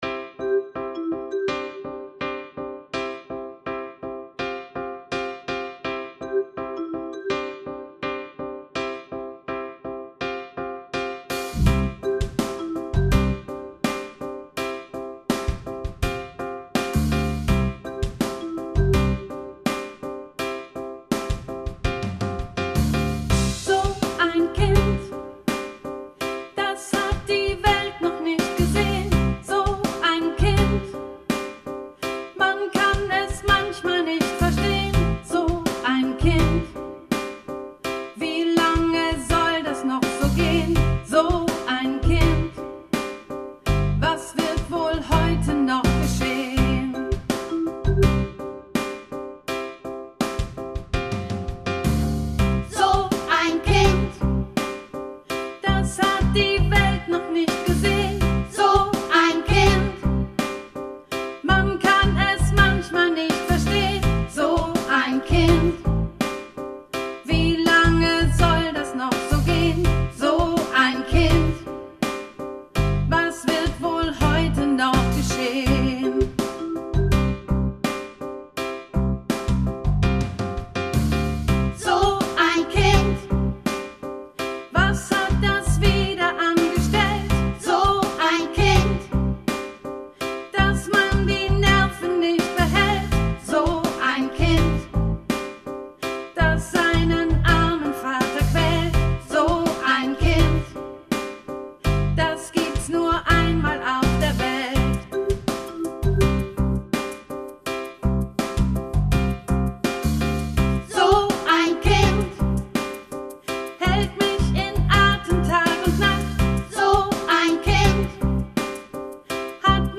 Lied